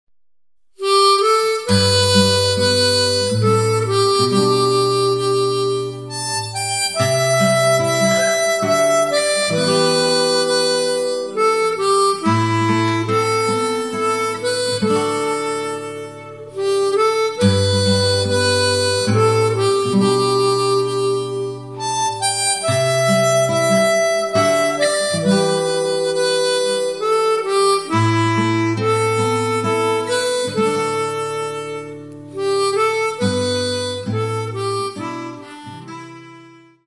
• Diatonic harmonicas
Acoustic guitar, bass guitar, fiddle, mandolin, vocals